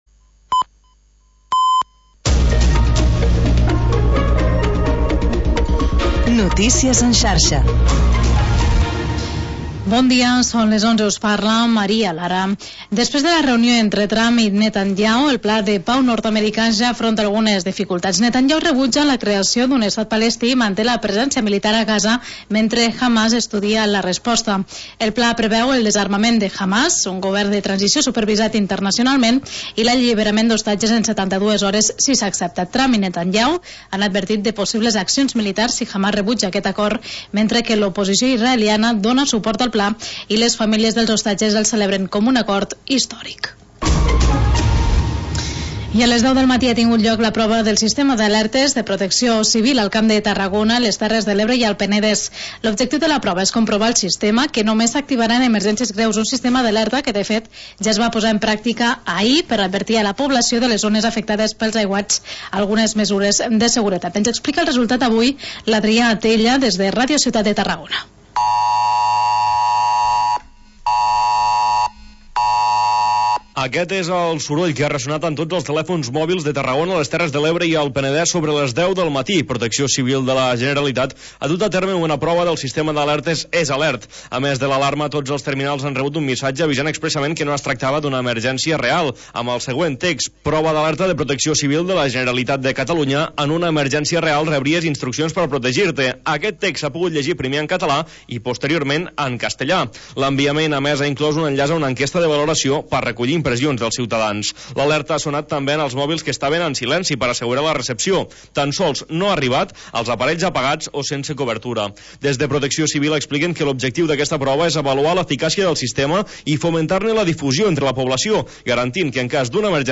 Magazín d'entreteniment